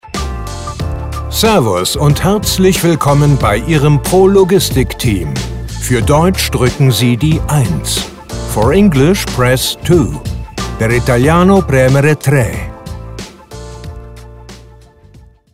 Professionelle Stimmen für die Begrüßung Ihrer Kunden ✓ Hollywood-Sprecher am Telefon ✓ AB-Ansagen ✓ Top Image ▶ Jetzt kostenlos beraten lassen!
Telefonansage Logistik